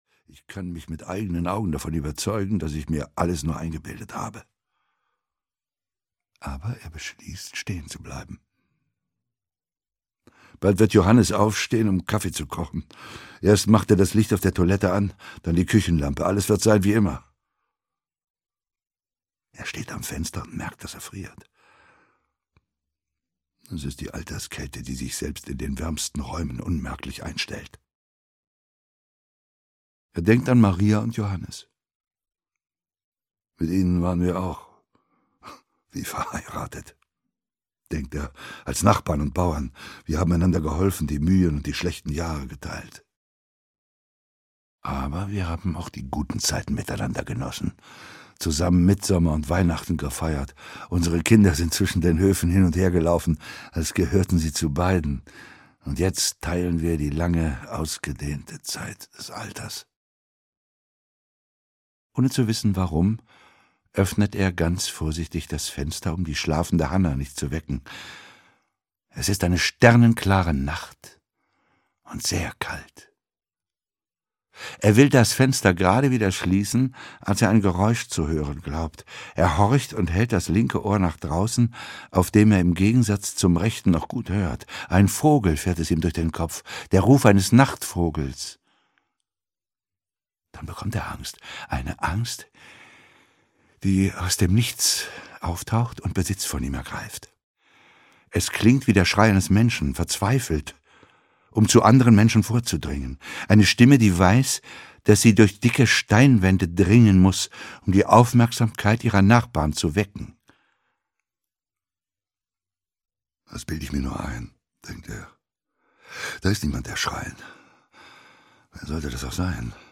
Audio kniha
Ukázka z knihy
• InterpretUlrich Pleitgen